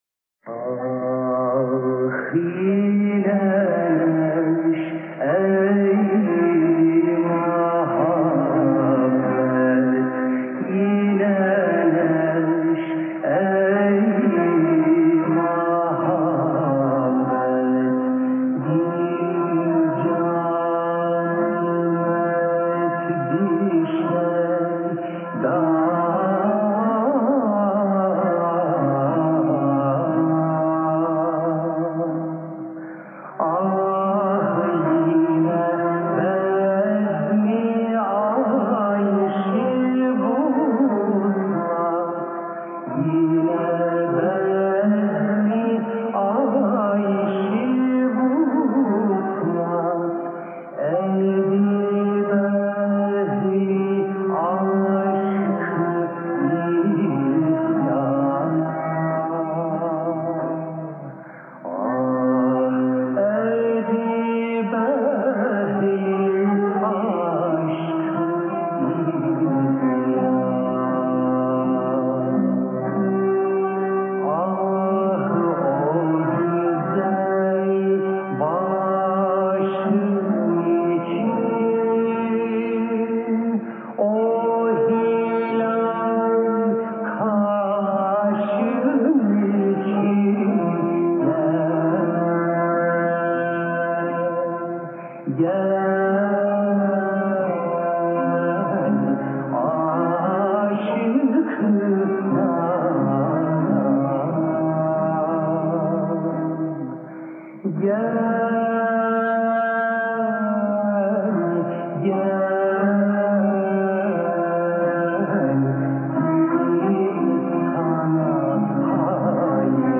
Sâniyen ,içinizde Tıphane-i Âmire’nin açılışı senelerinde,  Hamâmizâde İsmail Dede Efendi tarafından bestelenmiş, ve de sözleri büyük olasılıkla “Adlî” mahlâsı ile şiir yazan II. Mahmut’a ait hicaz eseri, rahmetli Zeki Müren tagânnî ederken (söylerken) dinleyeniniz var mı ?